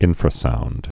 (ĭnfrə-sound)